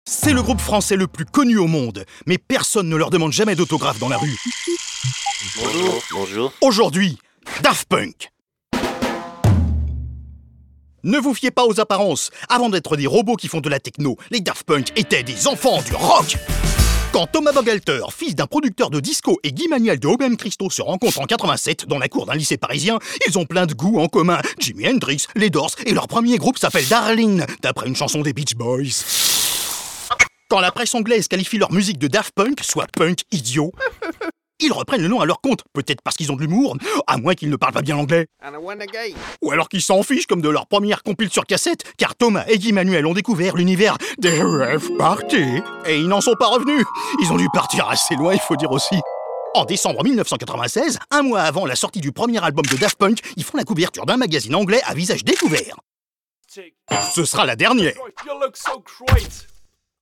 VOIX OFF – DOCUMENTAIRE: Daft Punk